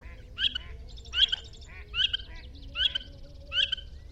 Eared Grebe